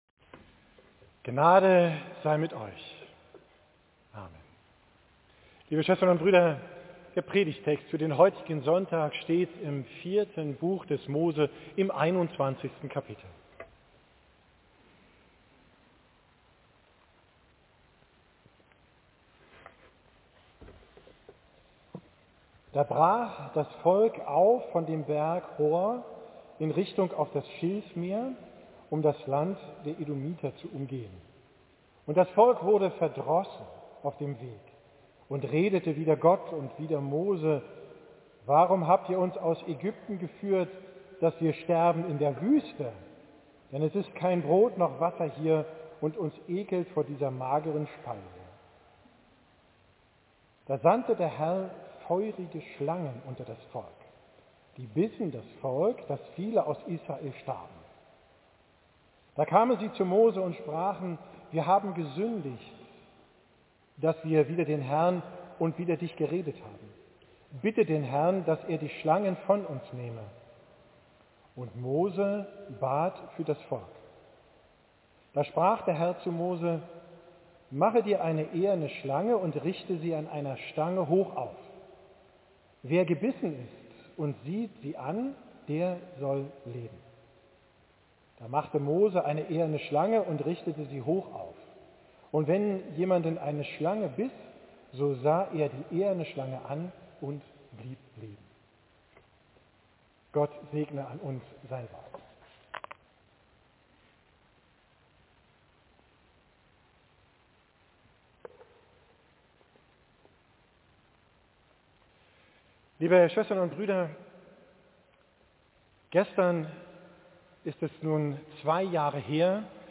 Predigt vom Sonntag Reminszere, 25.